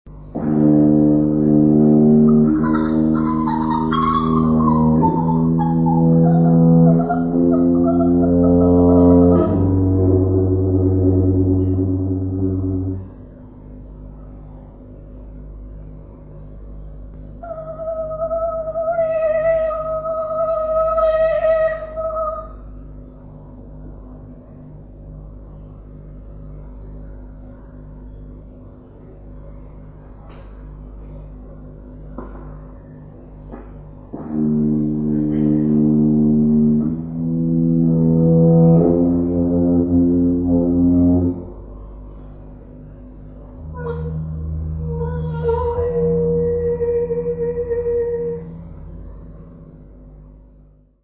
Environnement sonore. Audio installation.
Voix
Tuba
Guitare basse.